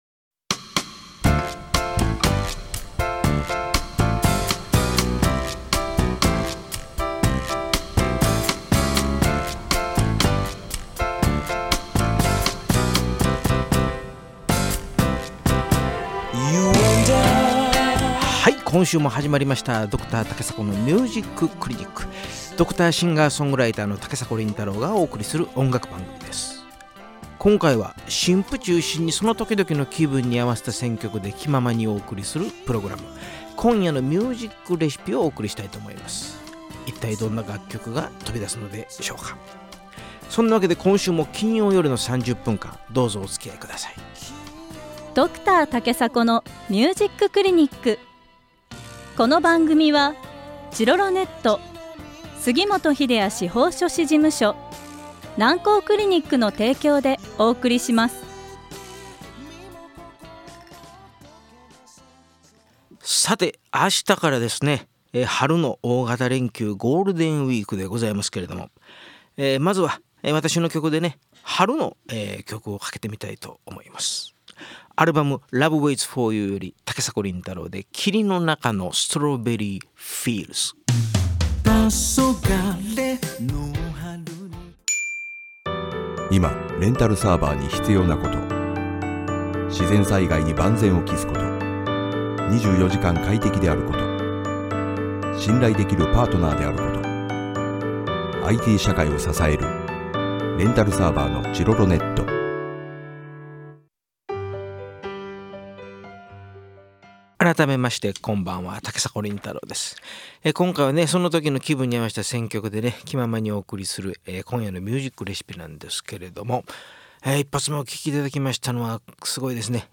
今回は新譜中心にその時の気分に合わせた選曲で気ままにお送りするプログラム「今夜のミュージック・レシピ」をお送りしました。